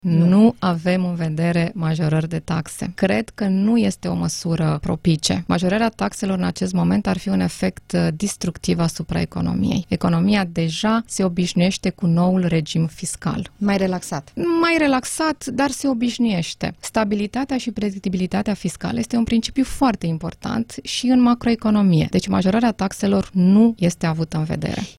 În contextul majorărilor salariale și creșterii indemnizațiilor pentru creșterea copilului, Guvernul nu ia în calcul o creștere a taxelor,  a mai spus ministrul Anca Dragu la Interviurile Europa FM.